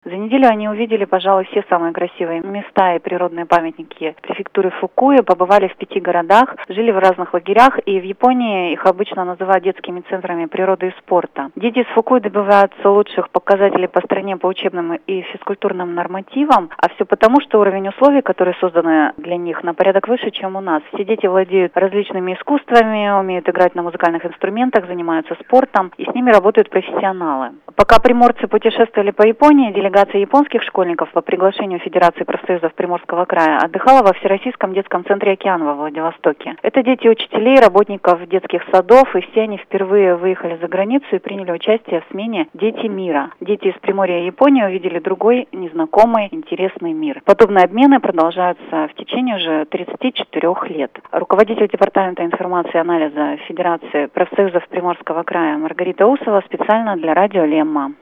Радио «Лемма», 21 августа 2011, в 15:00 и в 17:00